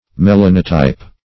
Search Result for " melanotype" : The Collaborative International Dictionary of English v.0.48: Melanotype \Me*lan"o*type\, n. [Gr. me`las, me`lanos, black + -type.]